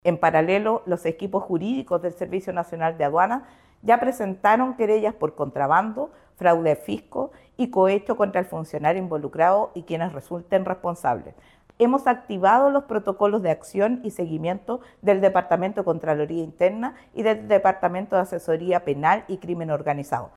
La Directora Nacional de Aduanas, Alejandra Arriaza, se refirió al caso del funcionario, afirmando que fueron activados todos los protocolos pertinentes y se tomarán las acciones penales correspondientes.